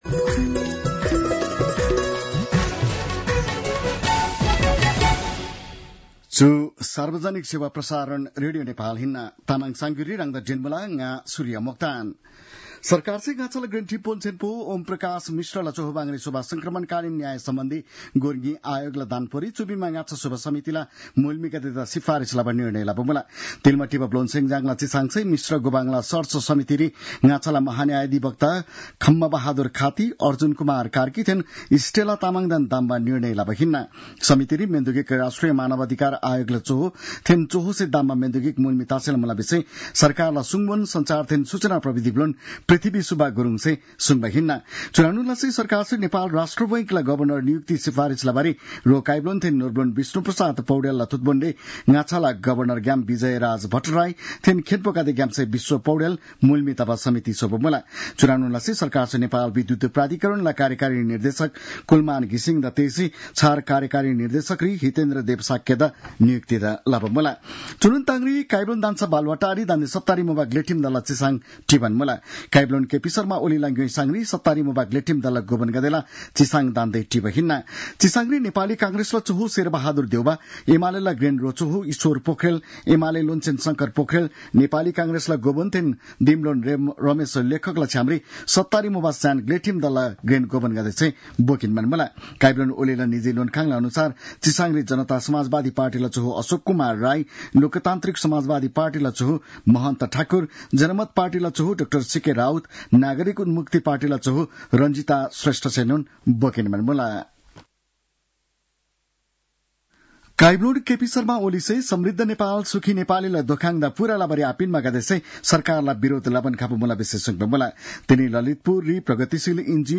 तामाङ भाषाको समाचार : १२ चैत , २०८१